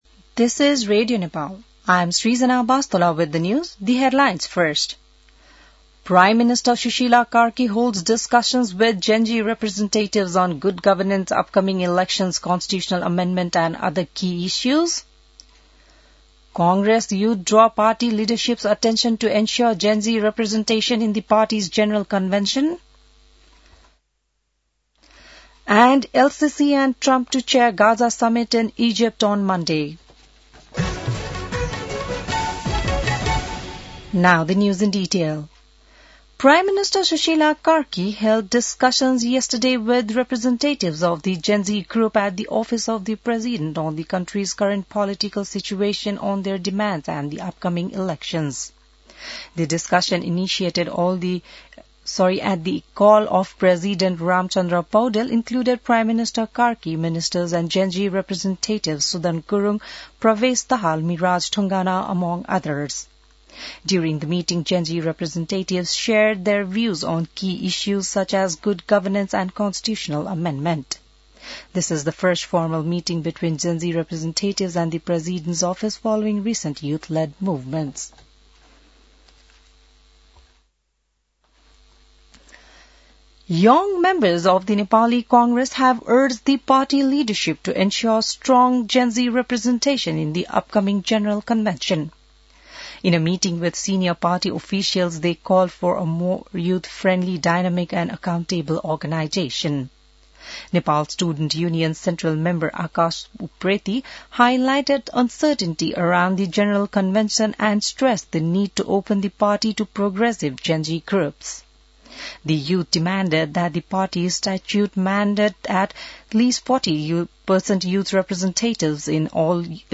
बिहान ८ बजेको अङ्ग्रेजी समाचार : २६ असोज , २०८२